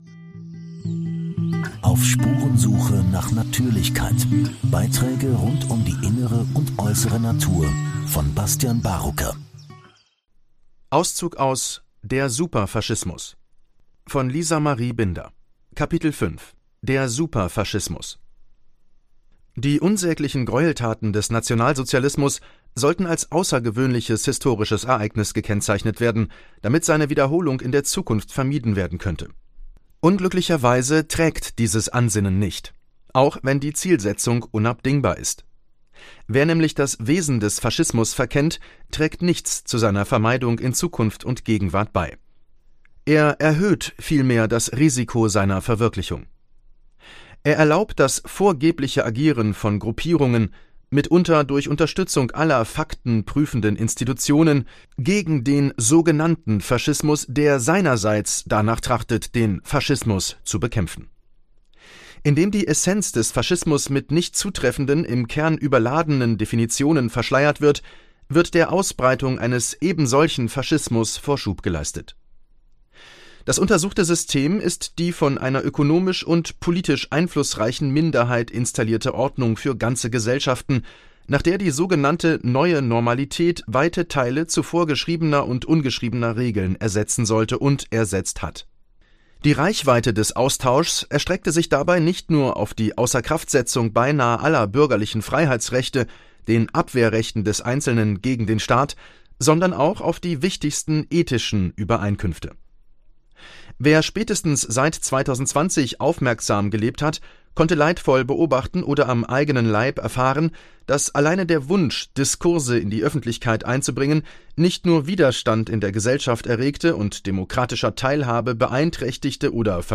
Buchauszug